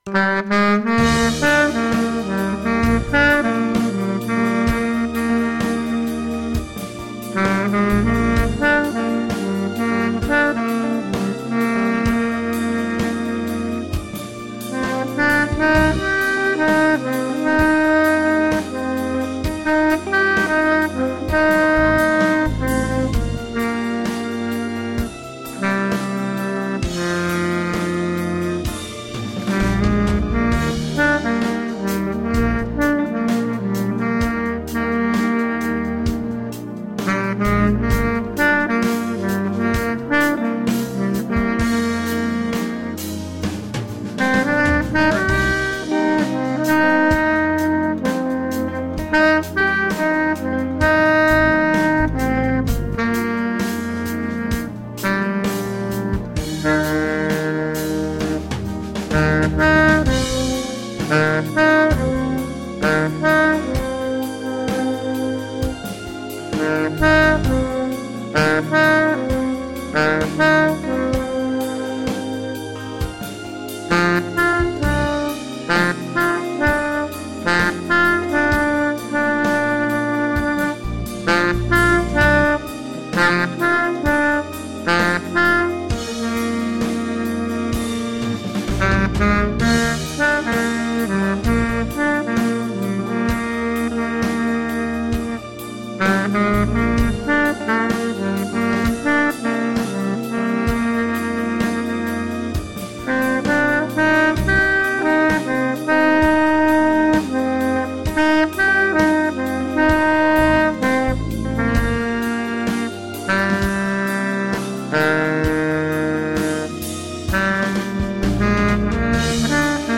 on tenor sax